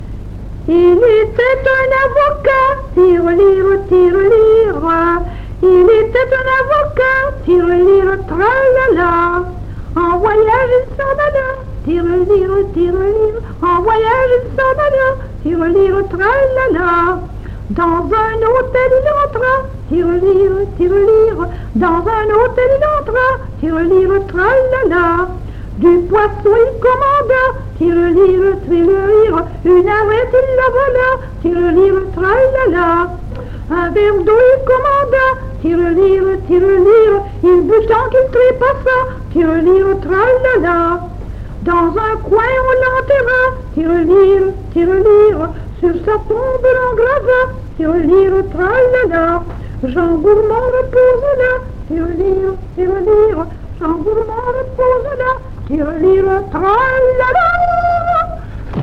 Genre : chant
Type : ronde, chanson à danser
Lieu d'enregistrement : Jolimont
Support : bande magnétique
Ronde.